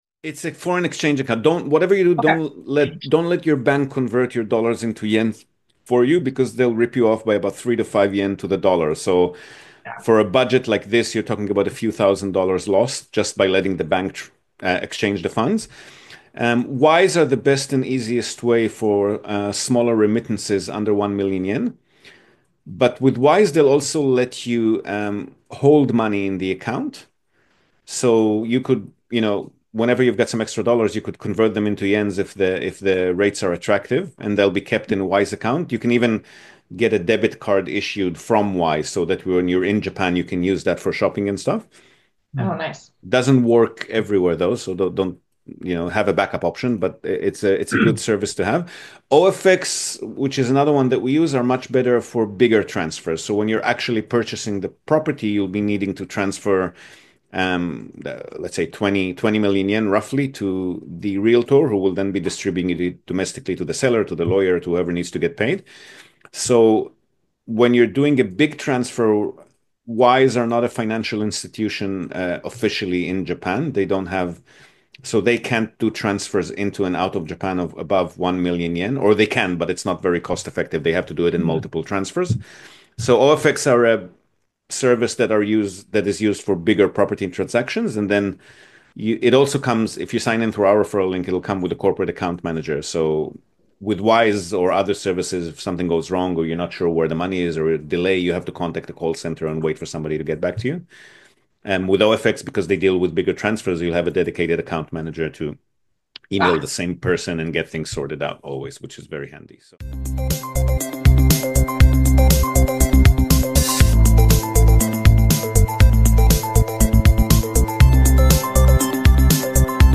Two back to back conversations with new clients, both in the market for older, cheaper houses, and both wanting to utilise them for income via short or medium term rentals. We talk locations, property profiles, tenants, guests, property management, and much more.